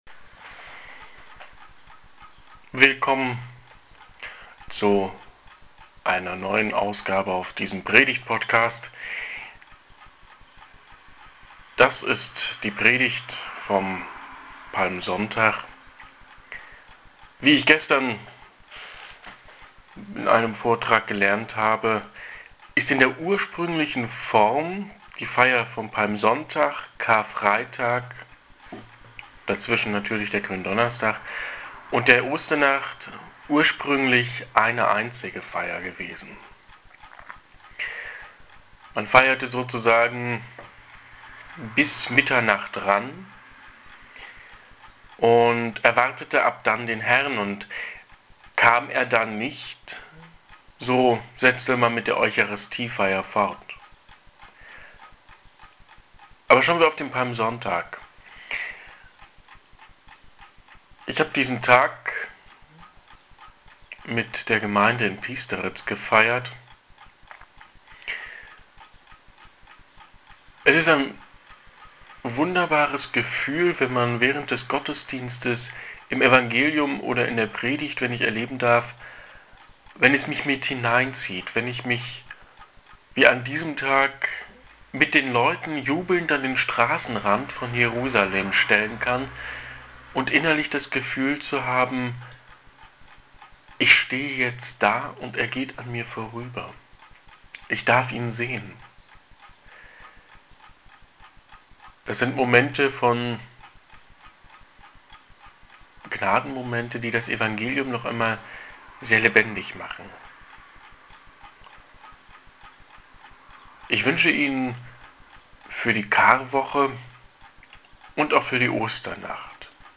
Predigt zum Palmsonntag